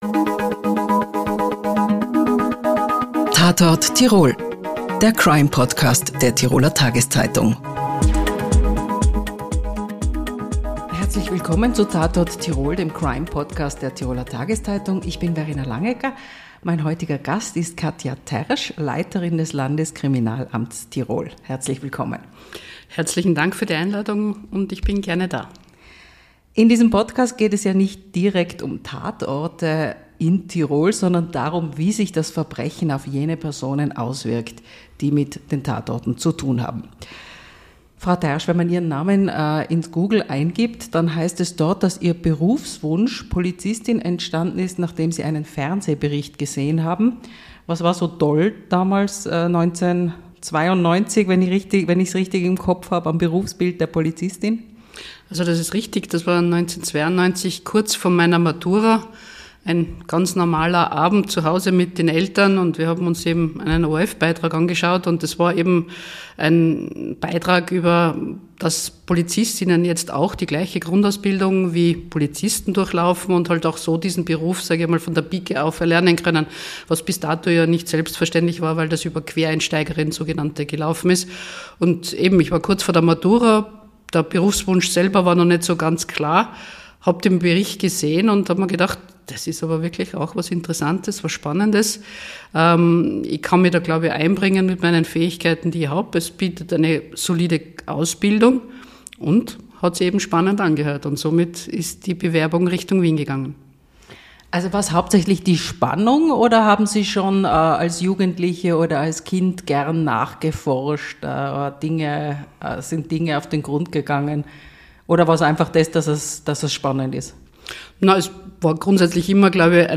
Gast der aktuellen Ausgabe ist Katja Tersch.